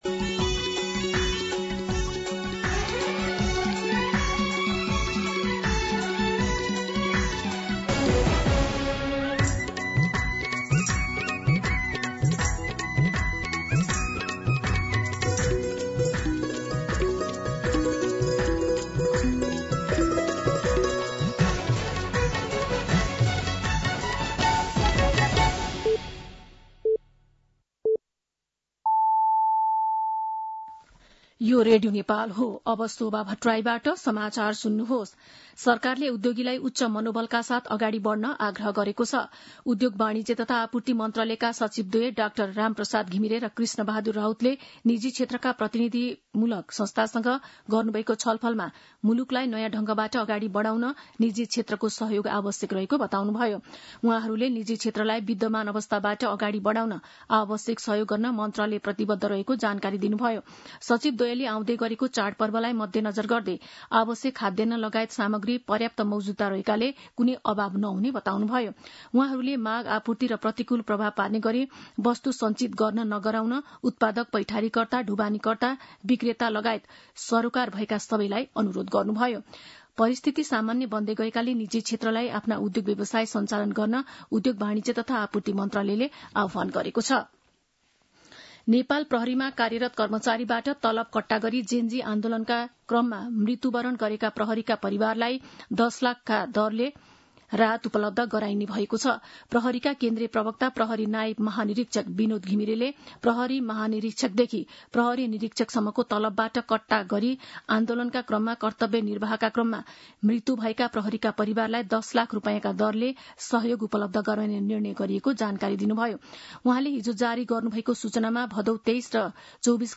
दिउँसो १ बजेको नेपाली समाचार : ३१ भदौ , २०८२